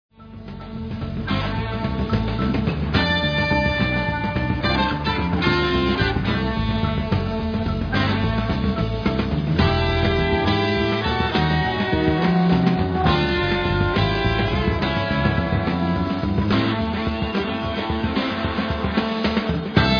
Southern (jižanský) rock